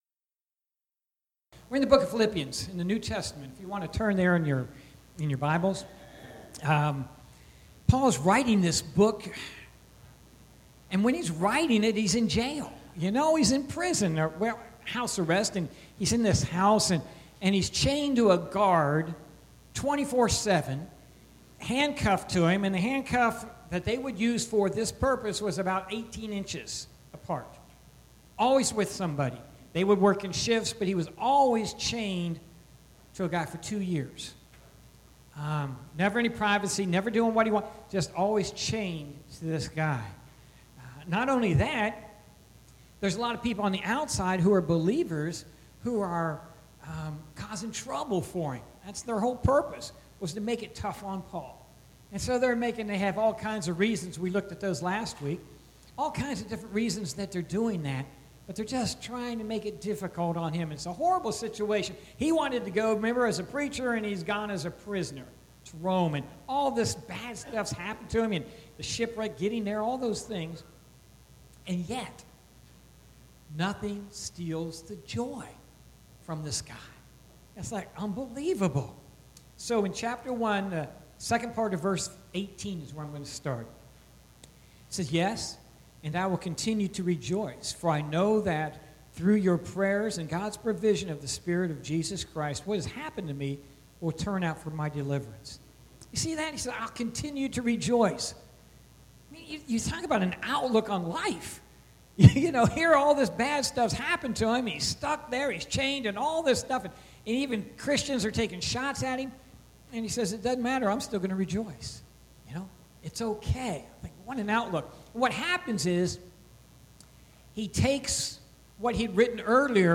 Sermons - Parkland Baptist Church